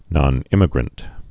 (nŏn-ĭmĭ-grənt)